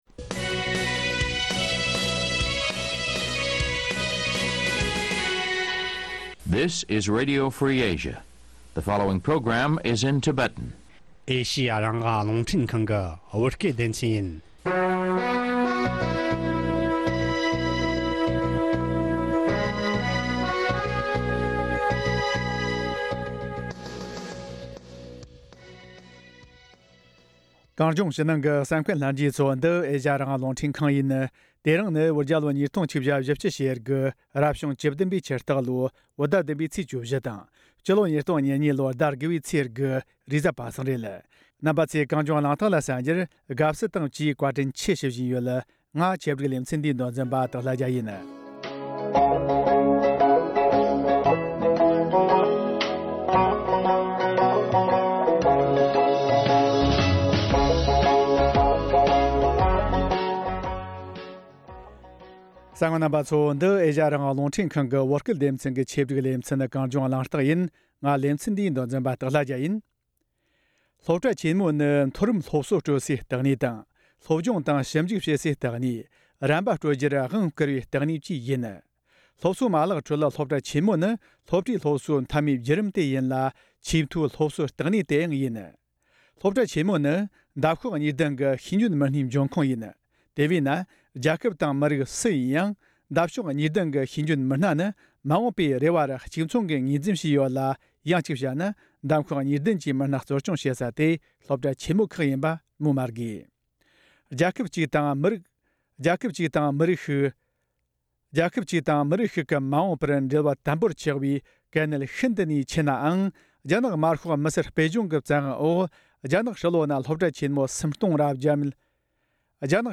བགྲོ་གླེང་ཞུས་པ